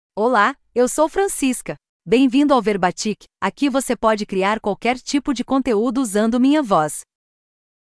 Francisca — Female Portuguese (Brazil) AI Voice | TTS, Voice Cloning & Video | Verbatik AI
Francisca is a female AI voice for Portuguese (Brazil).
Voice sample
Listen to Francisca's female Portuguese voice.
Francisca delivers clear pronunciation with authentic Brazil Portuguese intonation, making your content sound professionally produced.